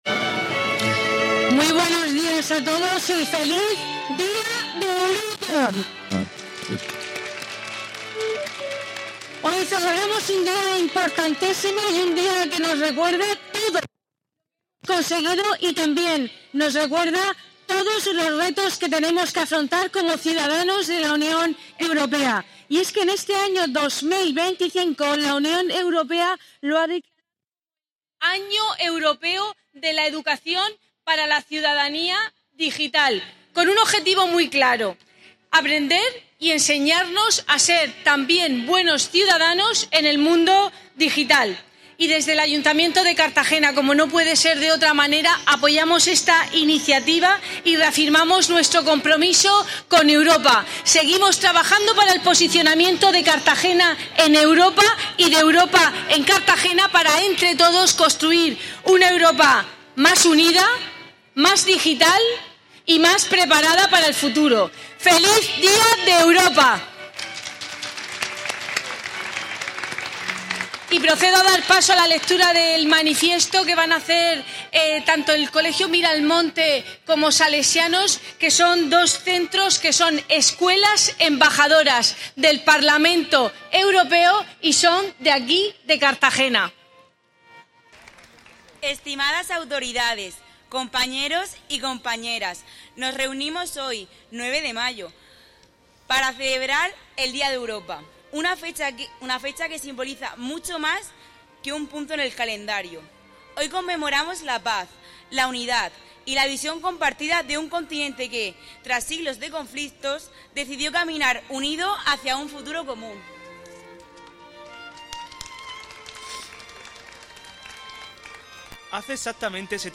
Izado de banderas europeas con motivo de la conmemoración del Día de Europa